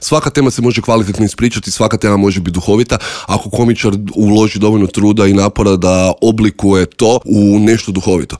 ZAGREB - Novu sezonu intervjua na Media servisu otvorili smo laganom temom.